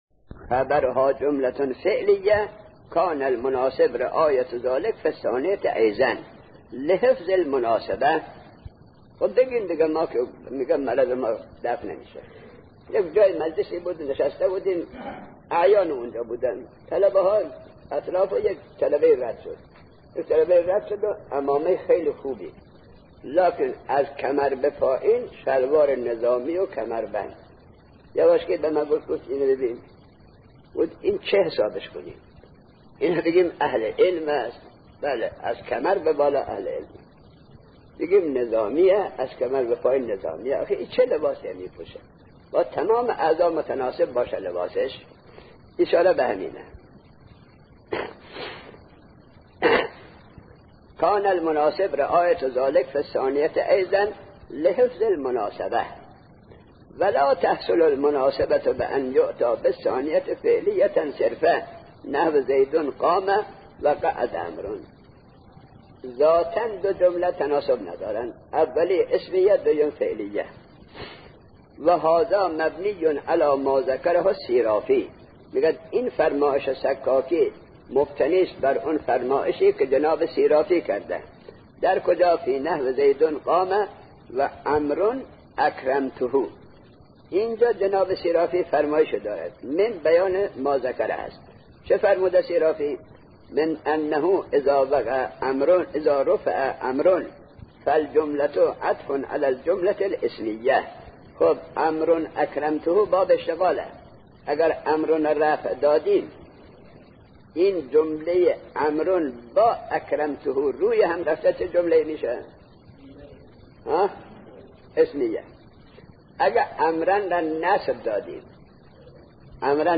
مرجع دانلود دروس صوتی حوزه علمیه دفتر تبلیغات اسلامی قم- بیان